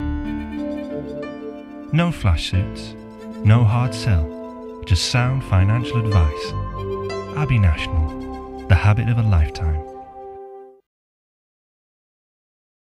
Voice Reel
Abbey National - Soothing, Warm, Reassuring